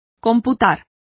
Complete with pronunciation of the translation of computes.